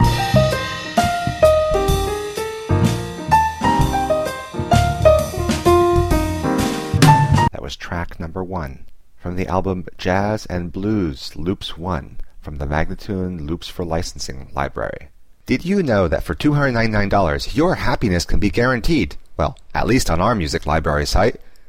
Instrumental samples in many genres.